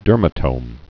(dûrmə-tōm)